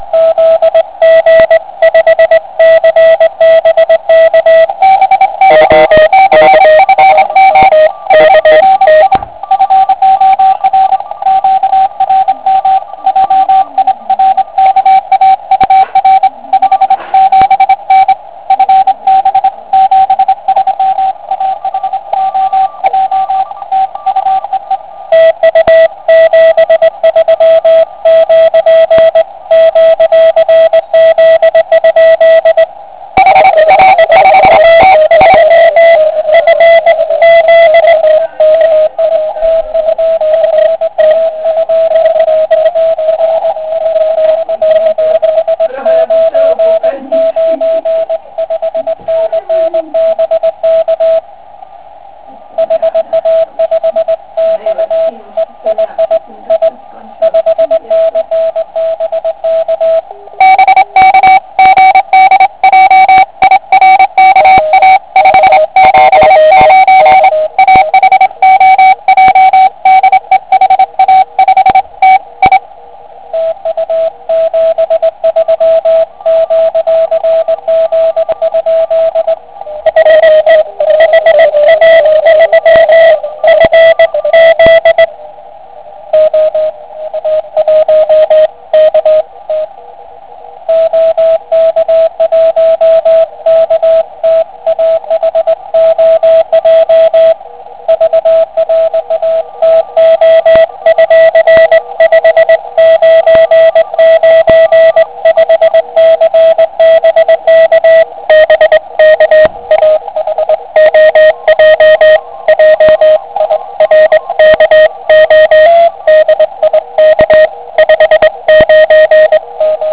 Zkrátka většinou vzniká krásný pile up.
Pravděpodobně tedy slyšel to co já v domácím QTH (PFR-3 nemá AVC a tak je to místy přemodulováno).
Podmínky šíření při Sotě LI-006 (neděle 11.10.2009 kolem poledne)
Všimněte si určité nekázně některých stanic při pile upu.